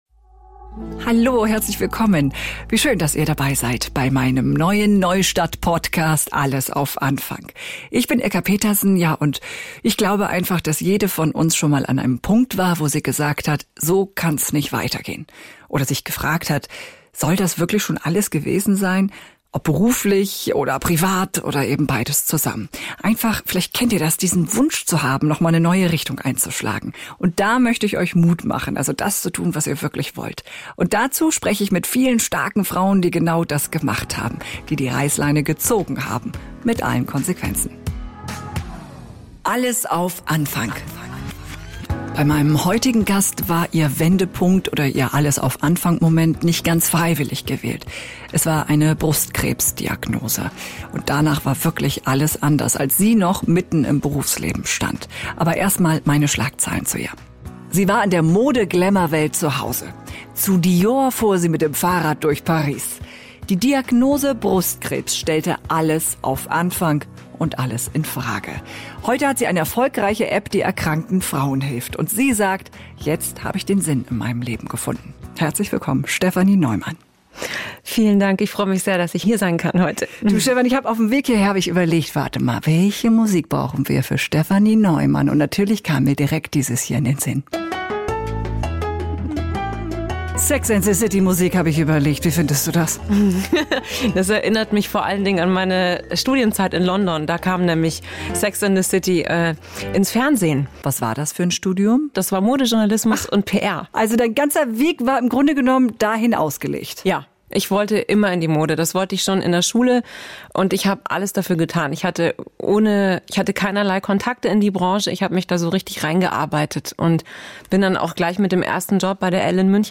im Gespräch mit einer beeindruckenden Frau, die ihr Leben nach der Krankheit komplett neu aufgestellt hat.